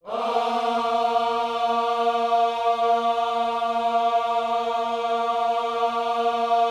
OHS A#3E  -R.wav